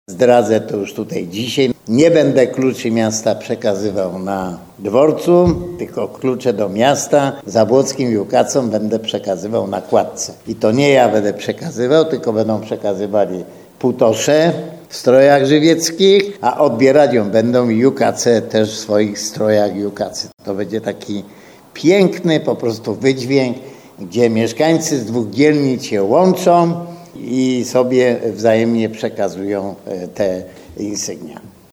– To będzie taki piękny wydźwięk, kiedy przedstawiciele dwóch dzielnic spotkają się i połączą przekazując sobie wspomniane klucze – mówi Antoni Szlagor.